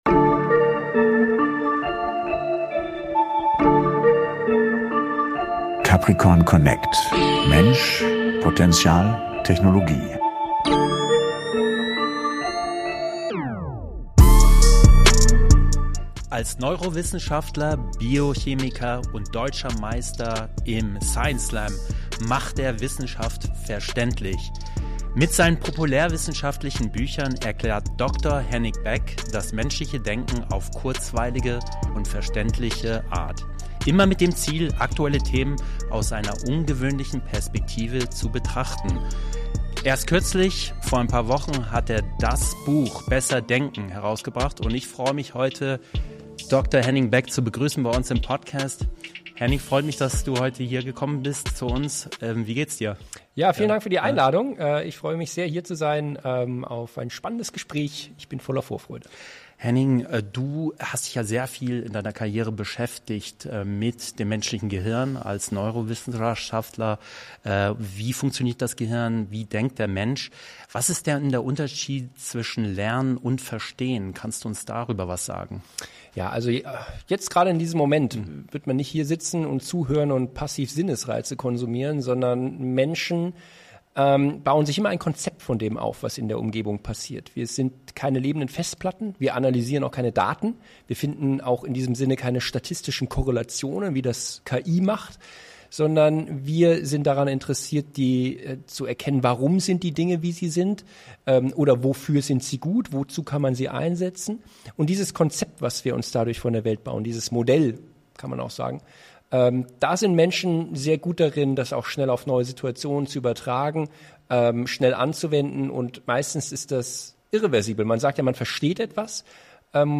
Gemeinsam diskutieren wir, wie KI unser Denken herausfordert, welche Rolle Emotionen und Motivation spielen und wie wir Denkfallen vermeiden können, um kreativ und zukunftsfähig zu bleiben. Ein inspirierendes Gespräch über Denken, Lernen, Führung, und die Kunst, unperfekt zu sein.